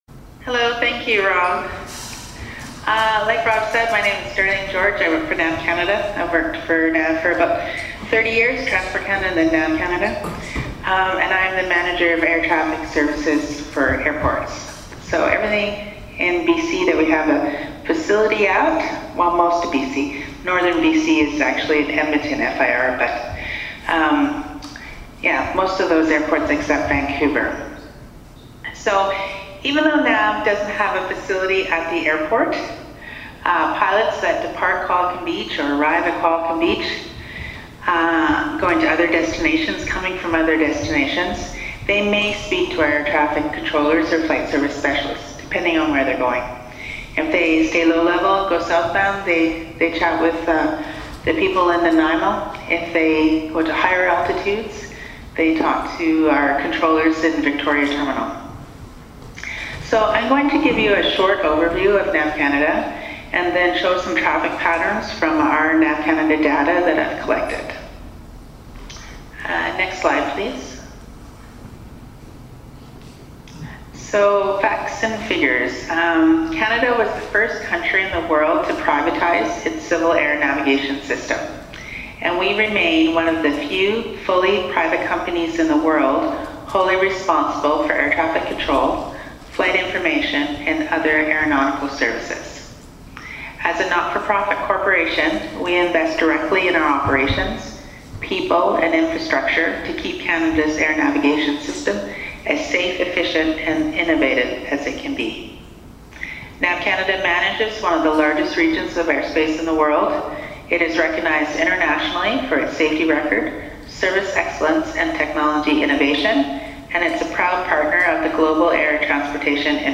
With permission from NAV CANADA, their presentation at the Airport Public Meetings was recorded and can be found here.